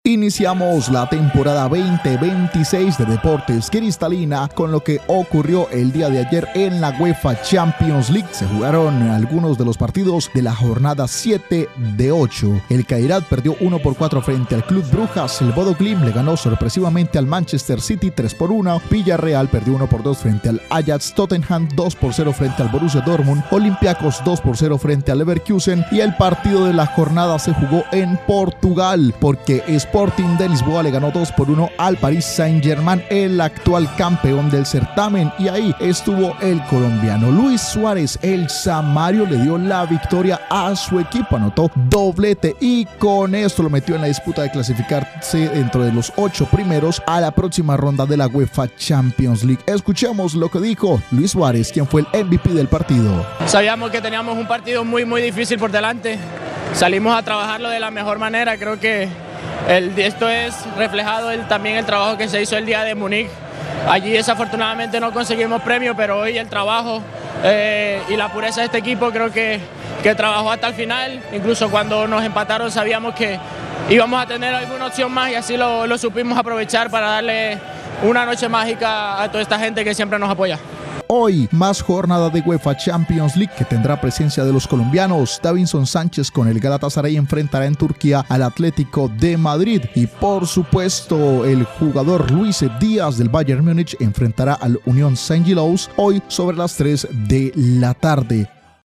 nota_deportiva.mp3